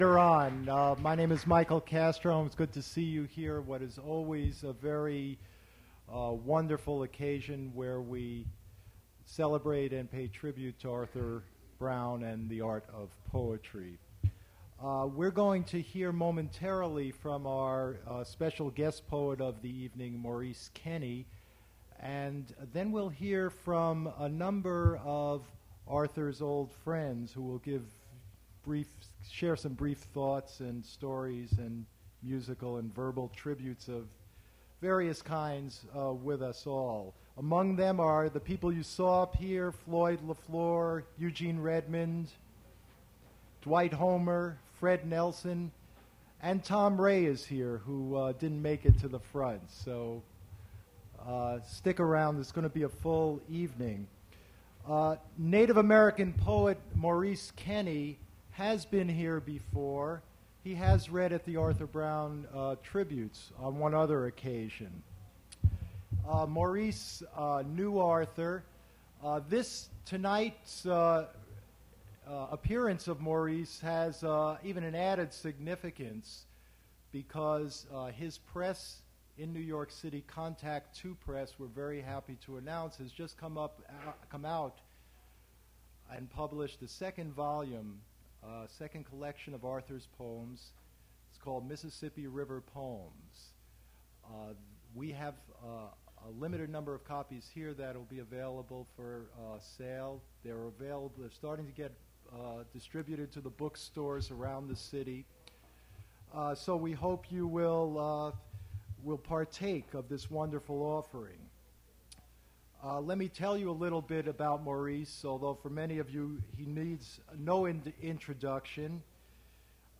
Poetry reading featuring Maurice Kenny
Attributes Attribute Name Values Description Maurice Kenny poetry reading at Duff's Restaurant.
mp3 edited access file was created from unedited access file which was sourced from preservation WAV file that was generated from original audio cassette. Language English Identifier CASS.728 Series River Styx at Duff's River Styx Archive (MSS127), 1973-2001 Note Cut beginning of the intro - music.